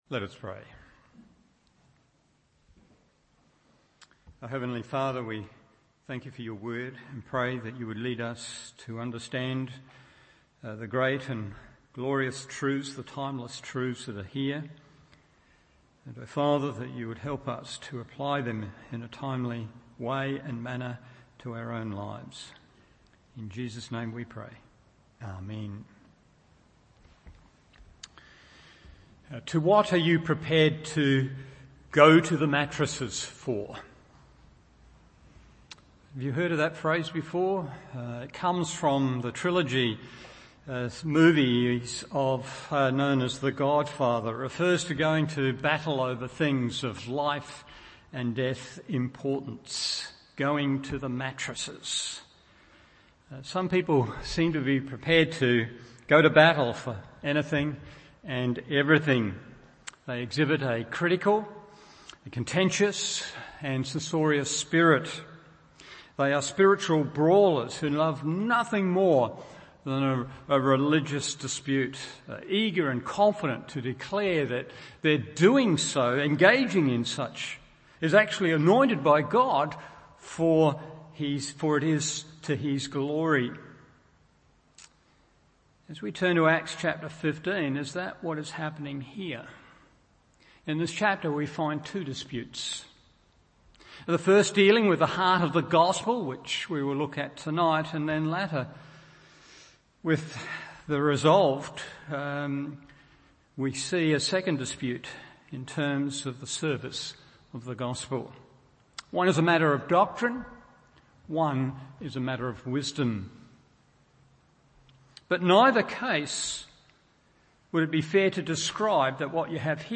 Evening Service Acts 15:1-35 1.